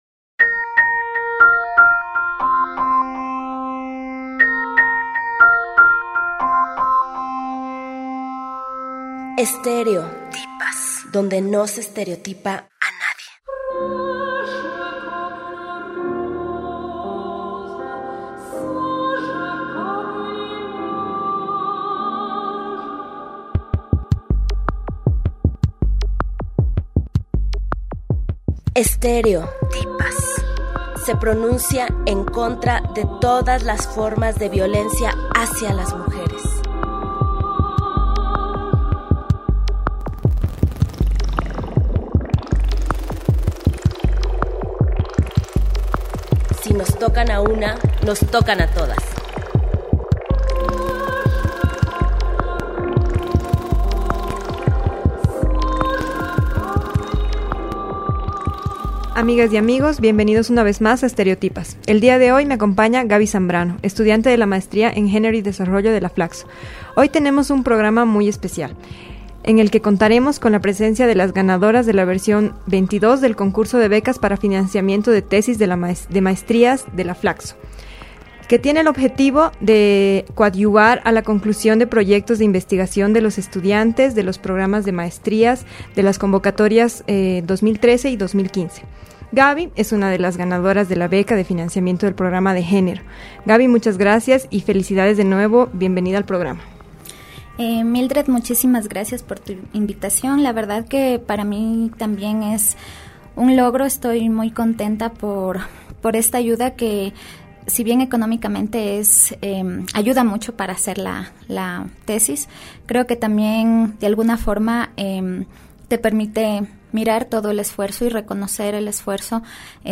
Estéreo-tipas, entrevistó a dos de las ganadoras de la convocatoria XXII del concurso de becas para financiamiento de tesis de maestría de la FLACSO, que tiene el objetivo de coadyuvar a la conclusión de proyectos de investigación de los estudiantes de los programas de maestría convocatoria a 2013 y 2015.